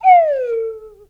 whistle_slide_down_01.wav